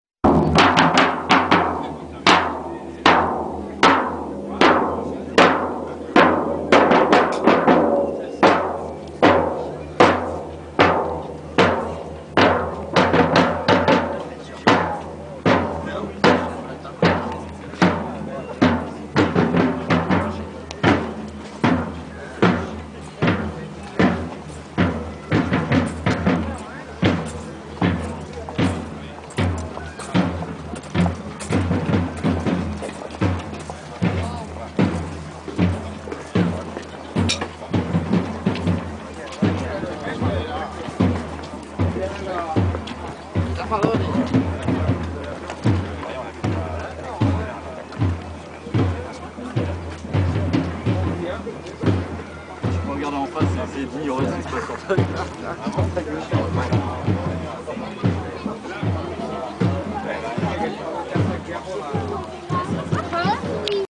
Medieval march
Biot 3 Medieval march.mp3